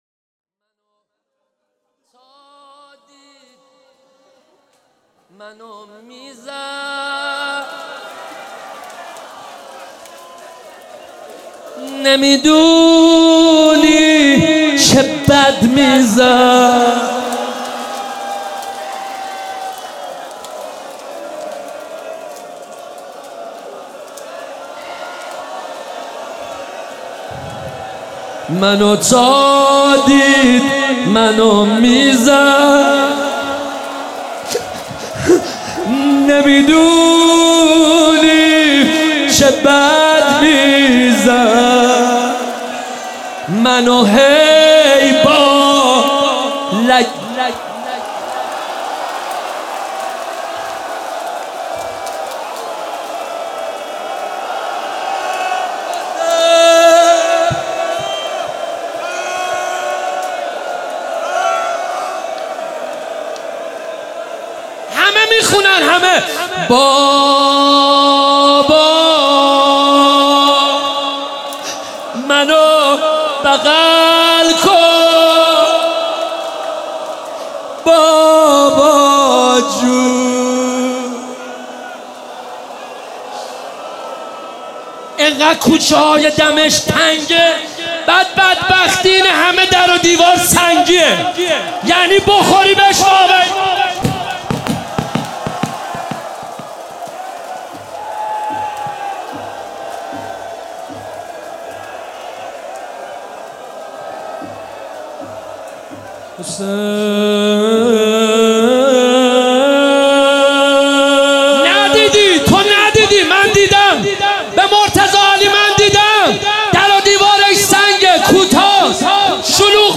روضه حضرت رقیه